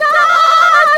SCREAM 8  -L.wav